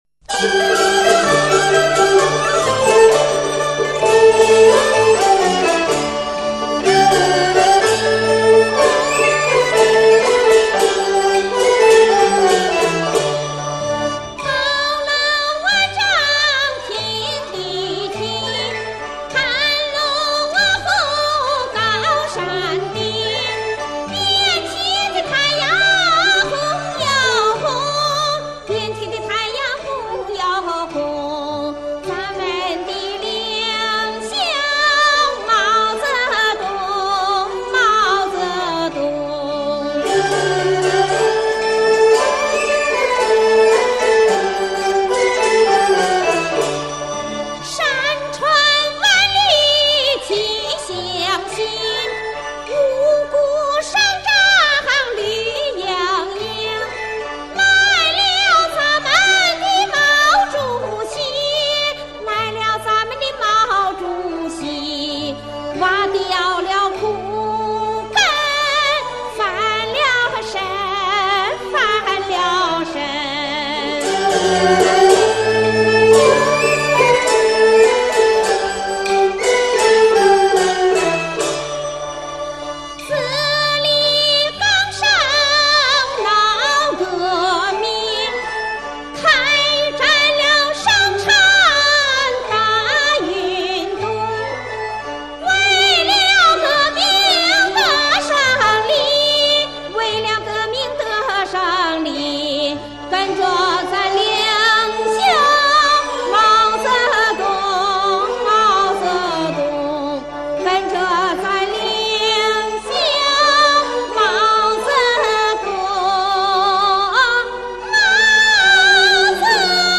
陕北民歌
（历史录音）